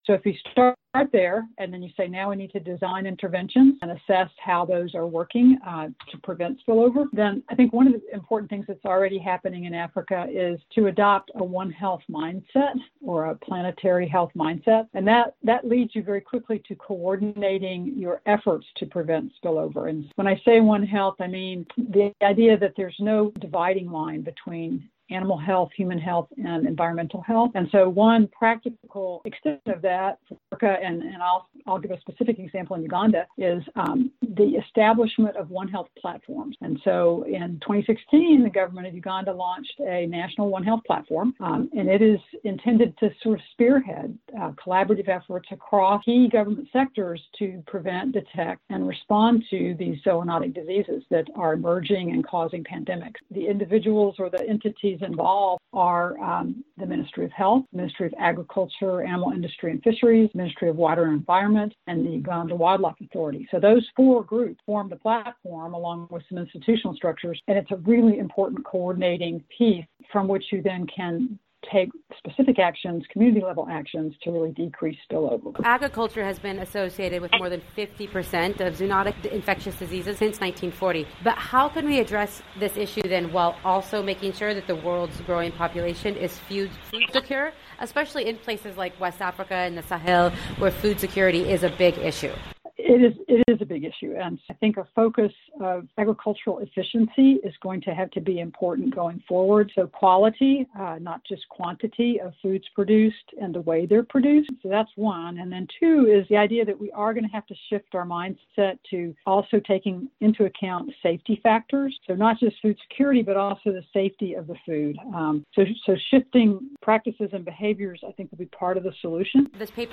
Humans, Animals, Environment All Interconnected - Report